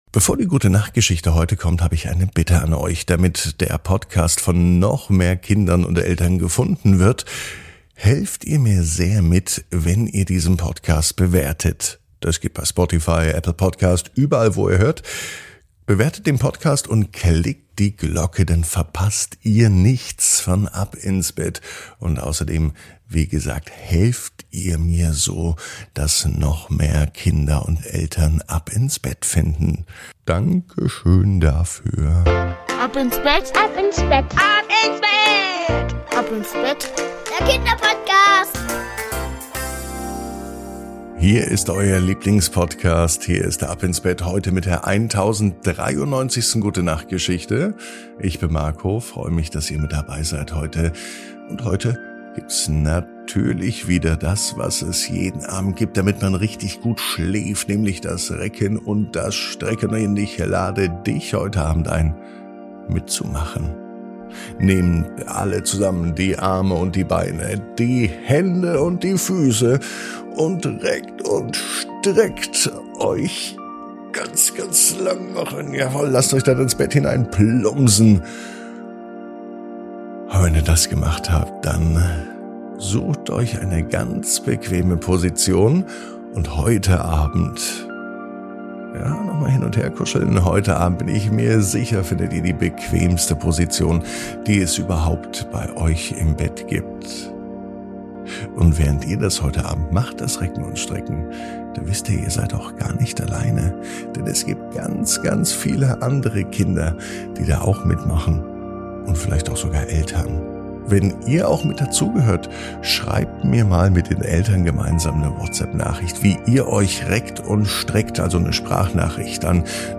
Die Gute Nacht Geschichte für Mittwoch